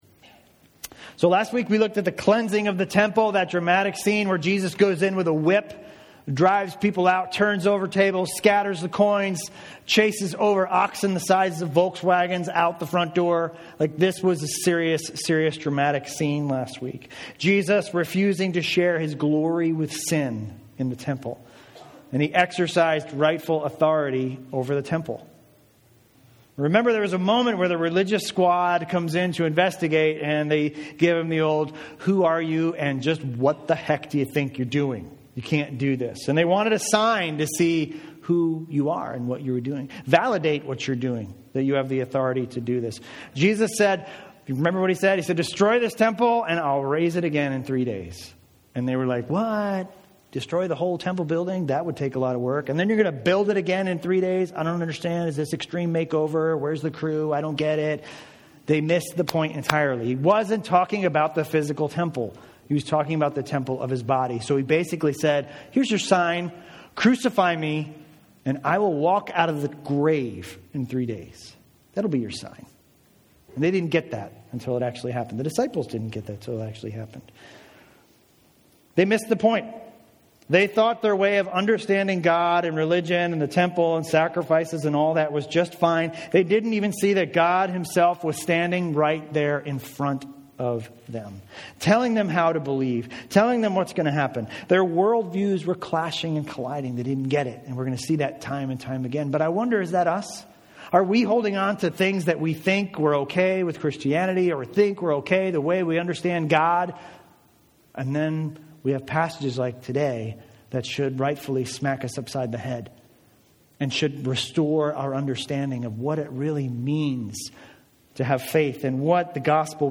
Message: “Bold Words”